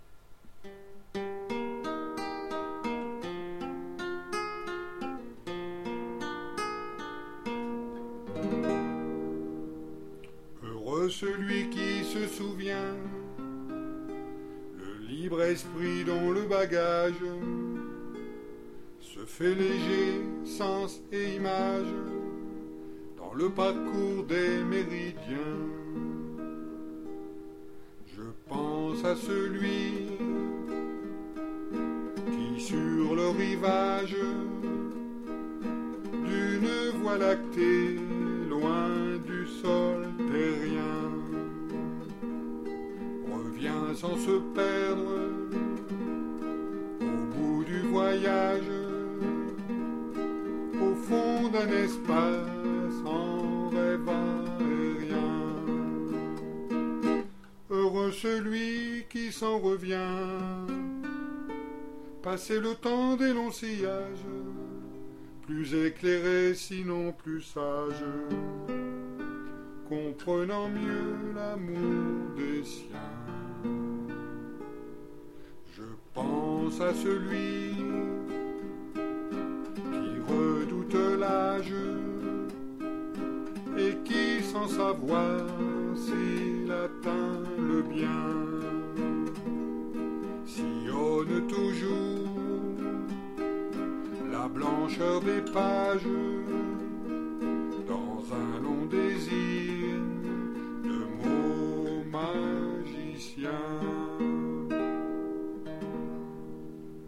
à la guitare